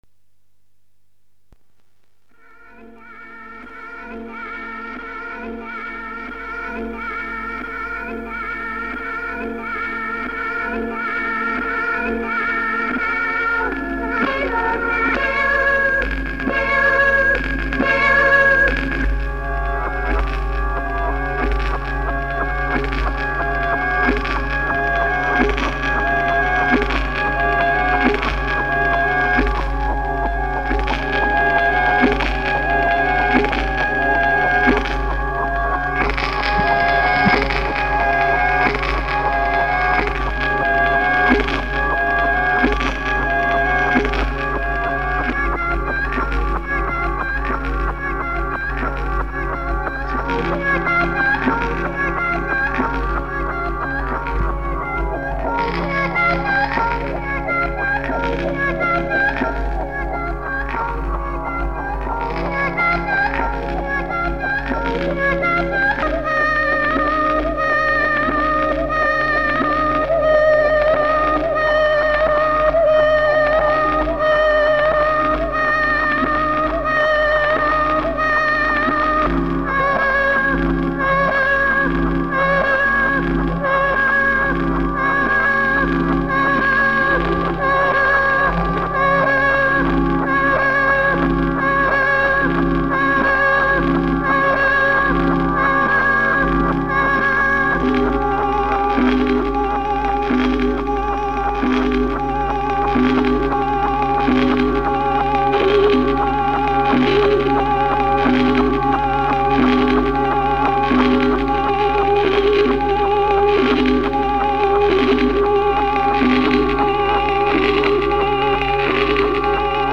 flexy disc e altoparlanti modificati
cori
chitarra.